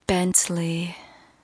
Tag: 请求 女性 女孩 谈话 声音 语音 美国 英语 说话